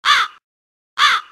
Nada notifikasi suara Gagak seram
Kategori: Nada dering
Suaranya creepy abis, cocok buat kamu yang suka bikin temen-temen kaget pas HP bunyi.
nada-notifikasi-suara-gagak-seram-id-www_tiengdong_com.mp3